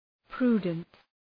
Προφορά
{‘pru:dənt}